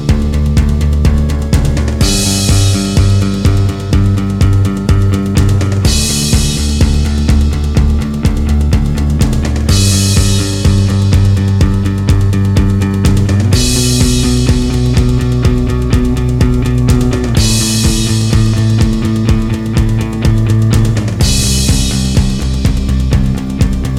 No Electric Guitar Indie / Alternative 4:37 Buy £1.50